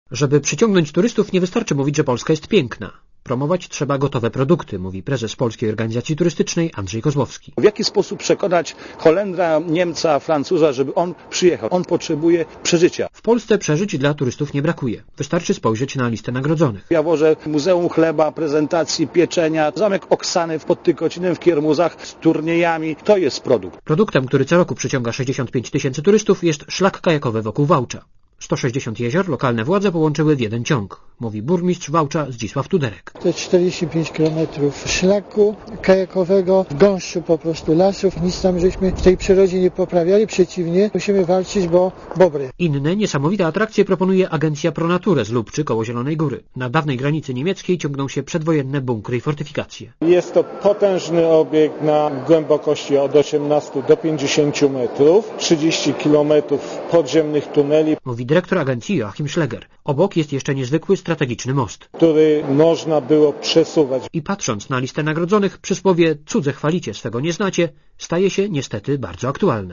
Komentarz audio (272Kb)